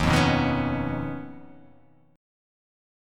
D#7sus2#5 Chord